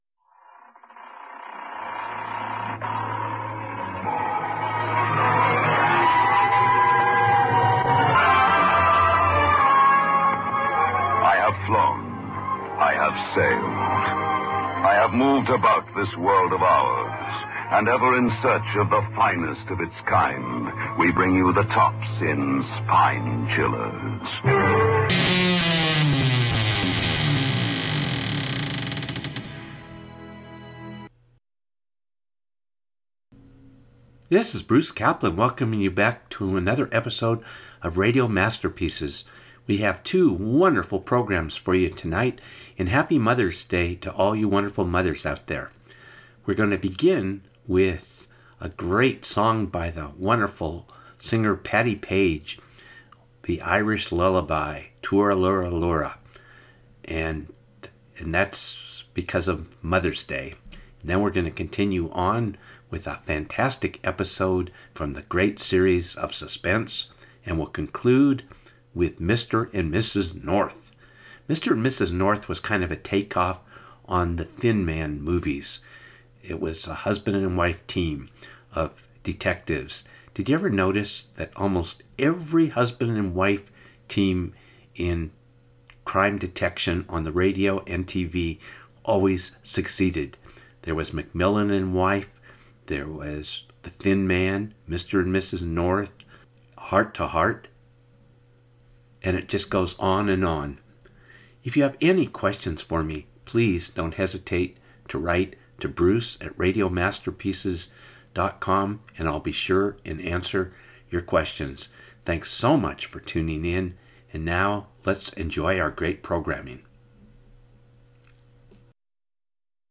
Listen to mystery theater as it was in the early days of radio and follow the greats such as “The Shadow”, “The Whistler” and much more.